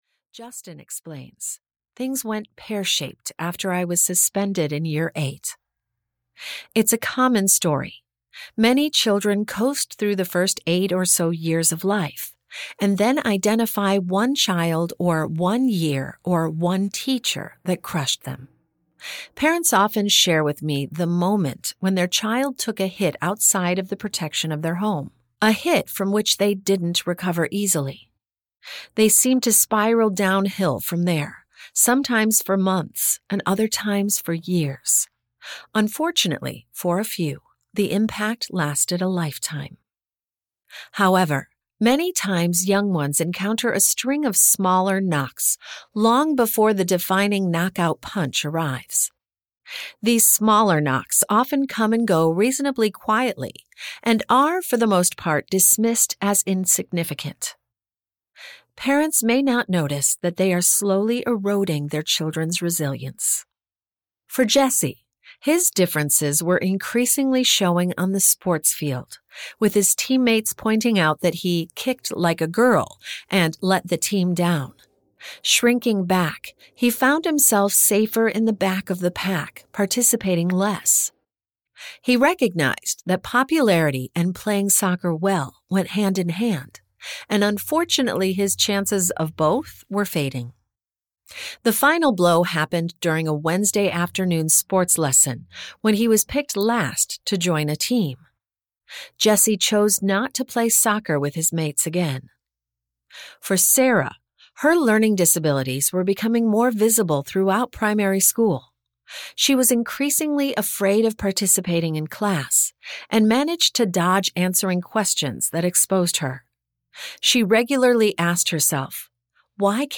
Everyday Resilience: Helping Kids Handle Friendship Drama, Academic Pressure and the Self–Doubt of G (EN) audiokniha
Ukázka z knihy